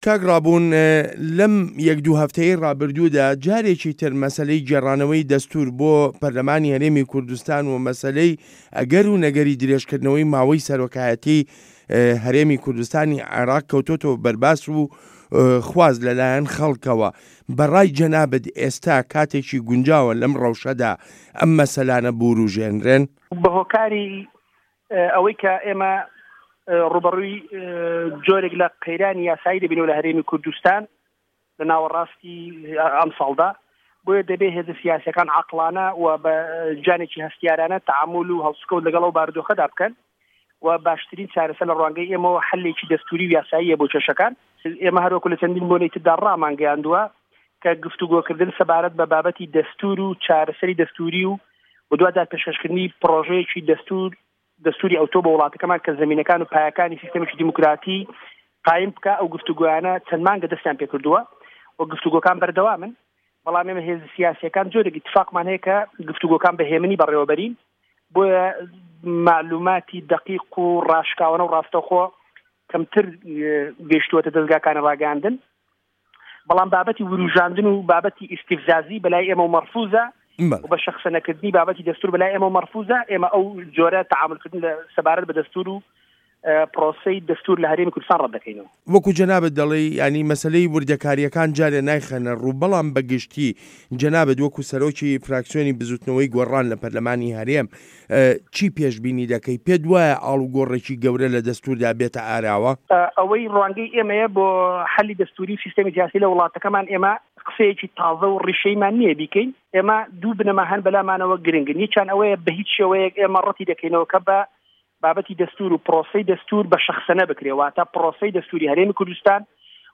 وتووێژ له‌گه‌ڵ ڕابوون مه‌عروف